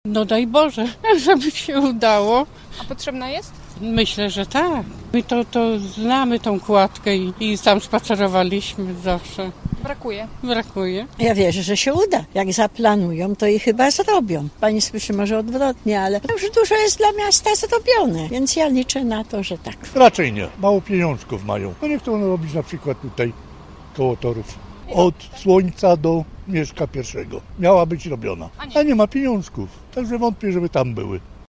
O opinie pytaliśmy także przechodniów – te są nieco bardziej optymistyczne: